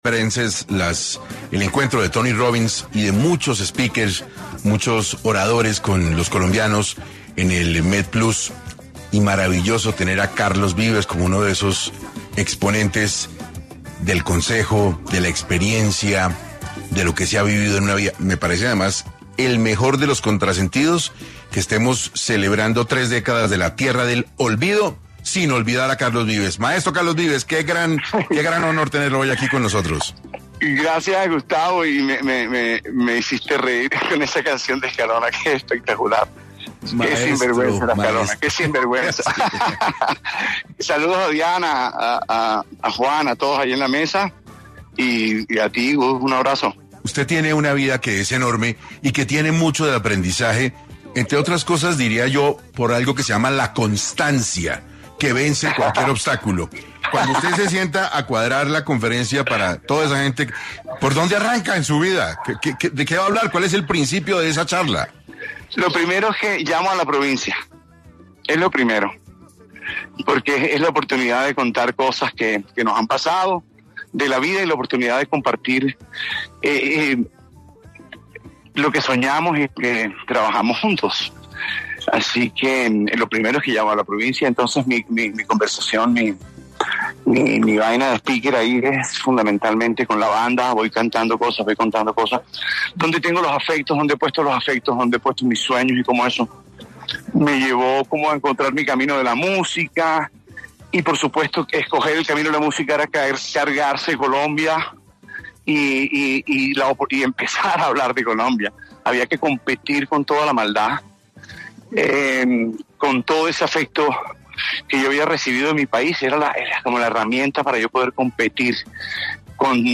En el programa 6 AM de Caracol Radio, estuvo Carlos Vives, cantautor y productor colombiano, quien habló sobre su experiencia y su camino en la música